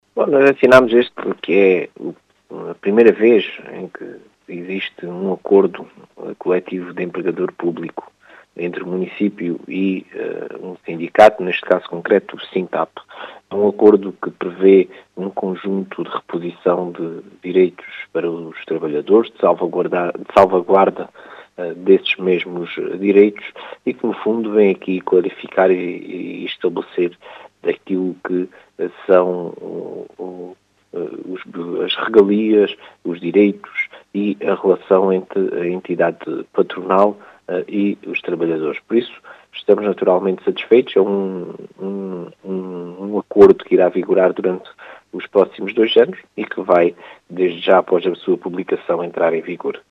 As explicações são de Marcelo Guerreiro, presidente da Câmara de Ourique.